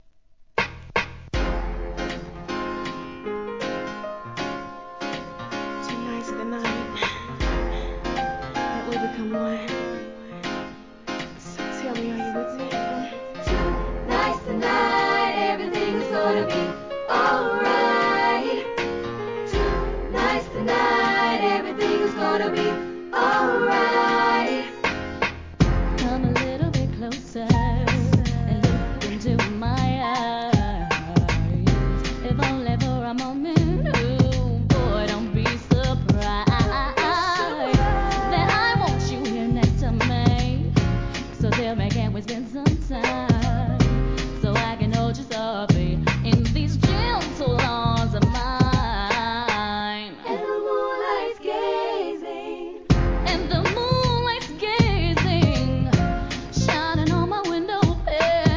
HIP HOP/R&B
JAZZYなREMIXは